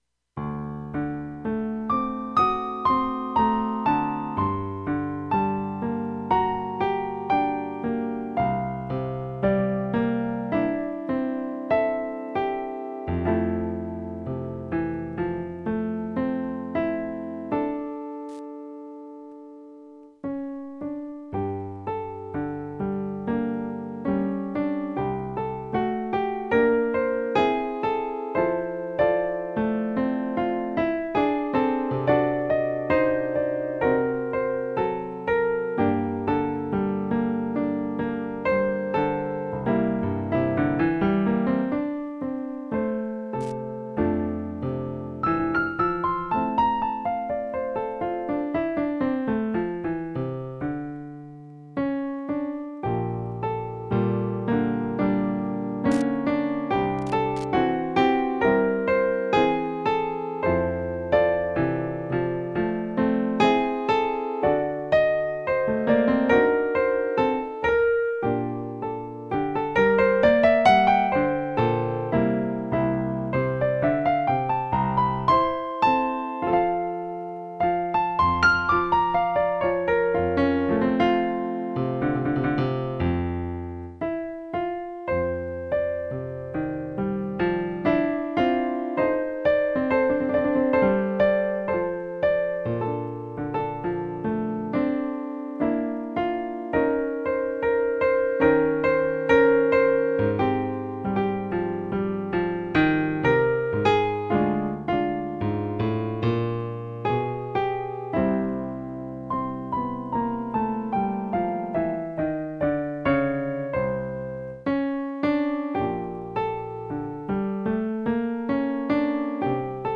Partitura para piano / Piano score (pdf)